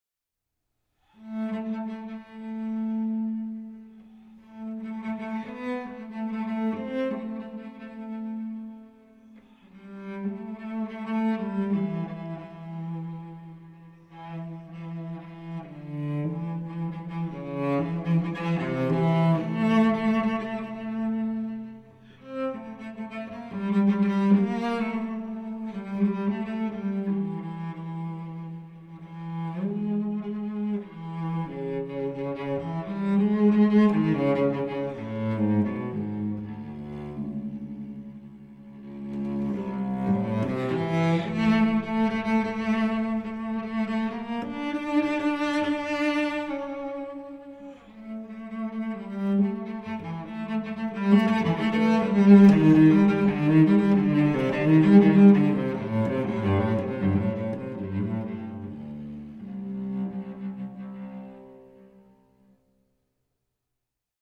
cello6:12